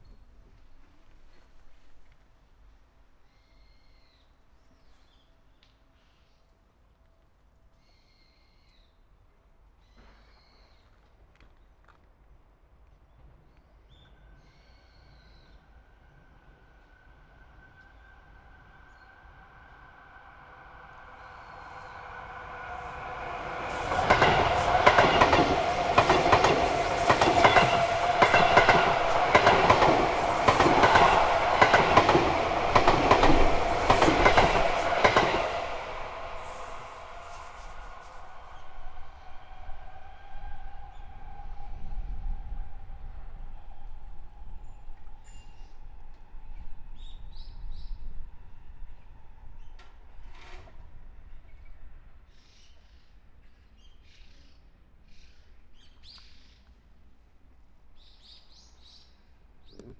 「清瀬カーブ」で、ZOOMのH2essentialとM2とM4の録り比べ
上り電車通過。
録音したファイルを、本体でノーマライズしました。
M2内蔵マイク＋へアリーウインドスクリーンWSU-1